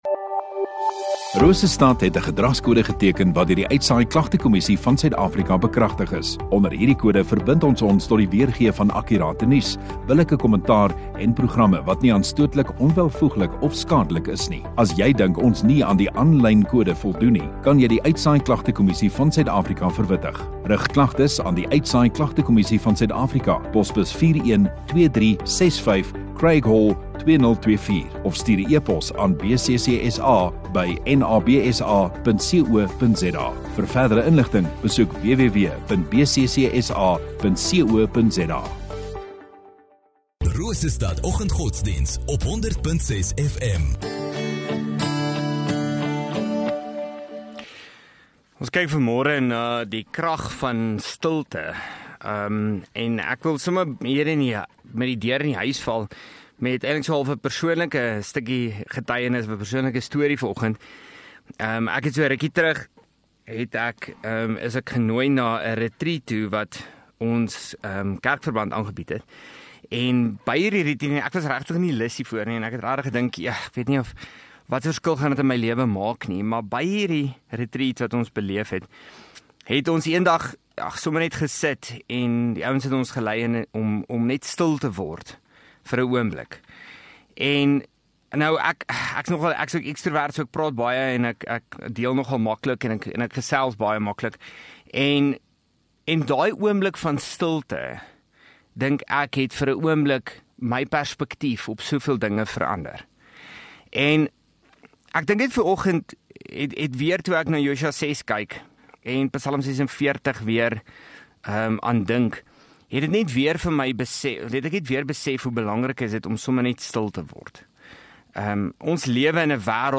24 Feb Dinsdag Oggenddiens